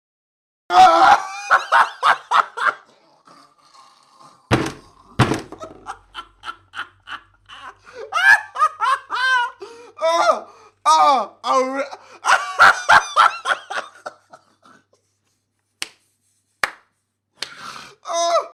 Download Laugh sound effect for free.
Laugh